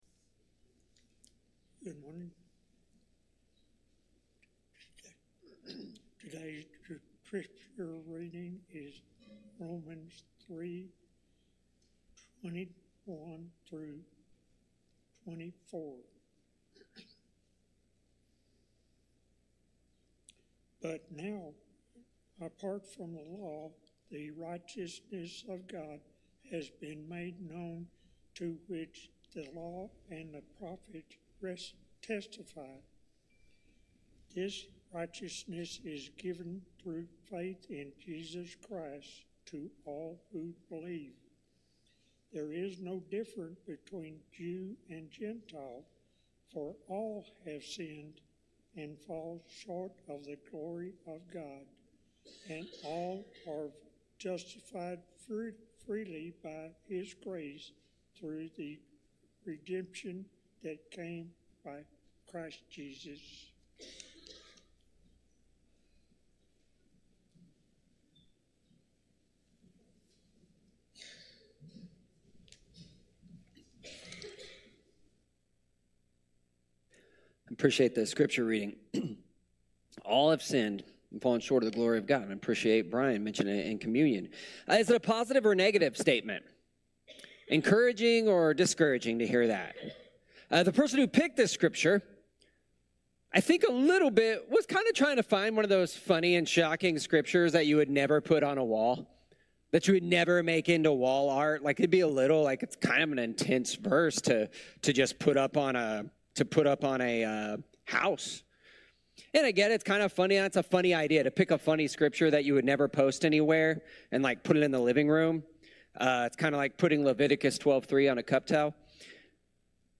We all sin, and this sermon will challenge us to self reflect on it. We will be challenged to look at our “acceptable sins” that we act like are no big deal, but really are damaging. We will also see how sin puts us all on the same playing field, but will never separate us from God’s love.